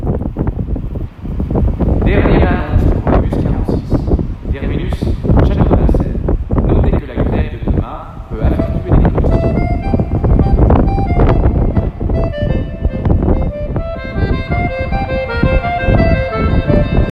Sillalle ripotelluista kaiuttimista kuului sesonkimusiikkia, mutta puhelin kuuli siinä tämmöistä (oli kova tuuli):